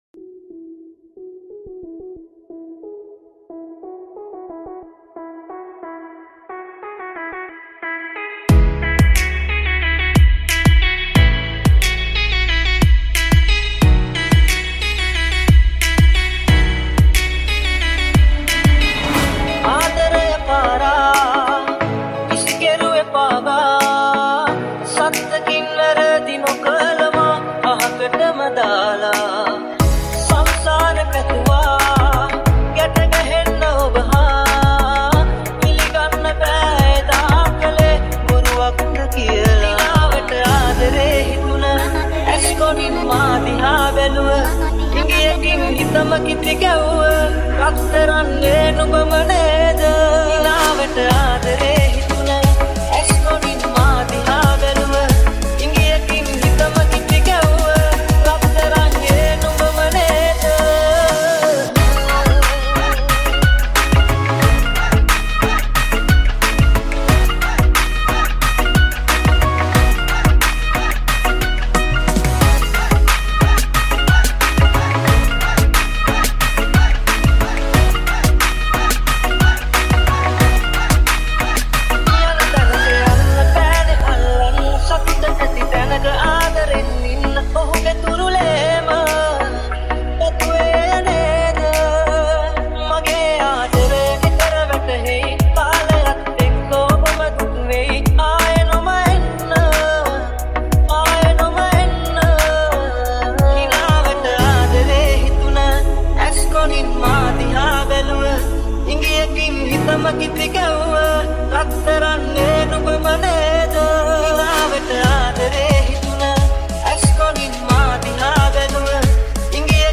High quality Sri Lankan remix MP3 (3.7).
high quality remix